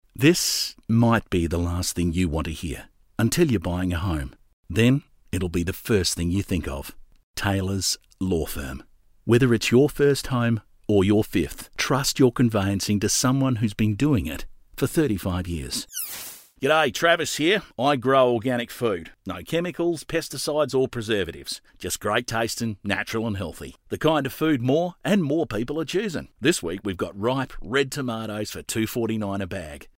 I guess you can call me ‘the everyday bloke’.
• Soft Sell